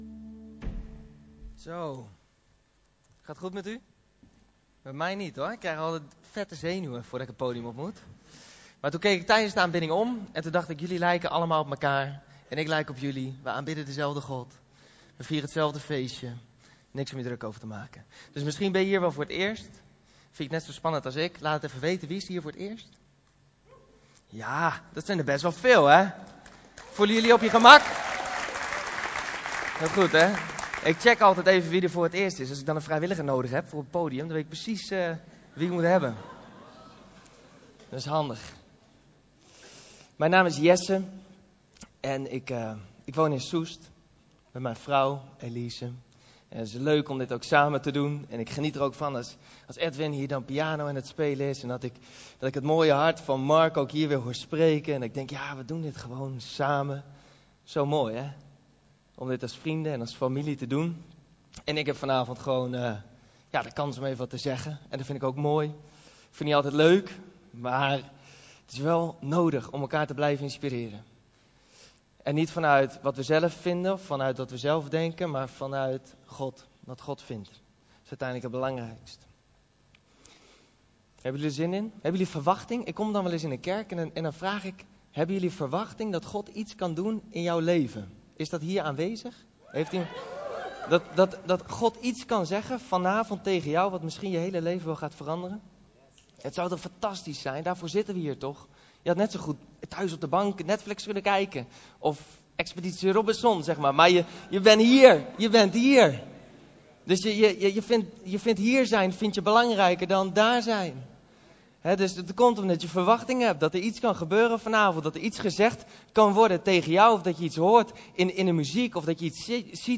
Om ook de woordverkondiging van de zondagse diensten te kunnen beluisteren op welk moment en waar je maar wilt, worden de preken ook als audio-uitzending gedeeld.